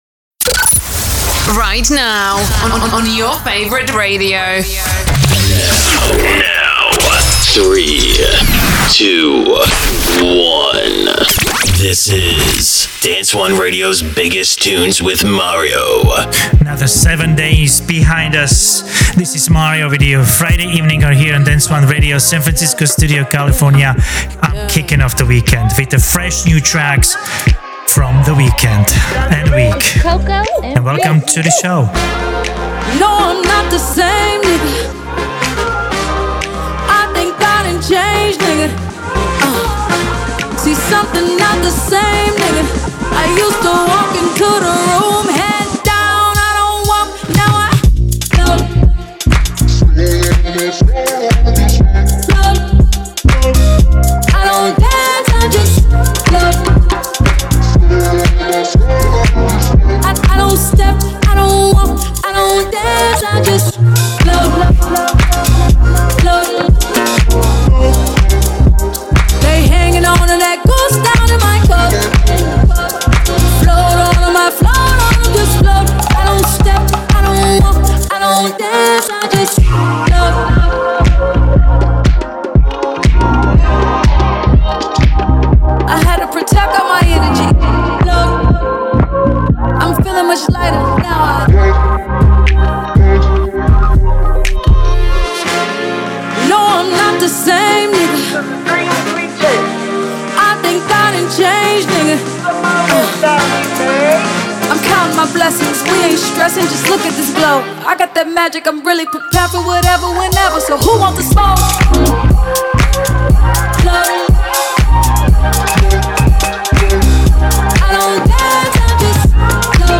Dance anthems that rule the dance and electronic scene